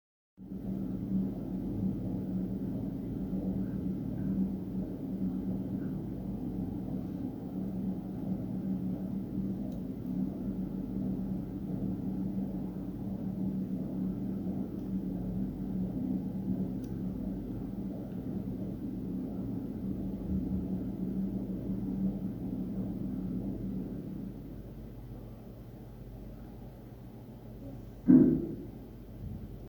25-26 января 2020г. провели работы по замене низкочастотного преобразователя (возможно название неправильное). Звук немного изменился, но существенных отличий нет:
шум от лифта после работ